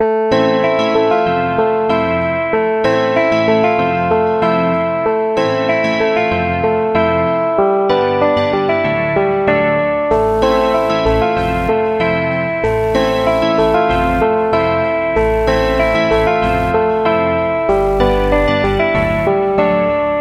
Tag: 95 bpm Weird Loops Piano Loops 1.70 MB wav Key : A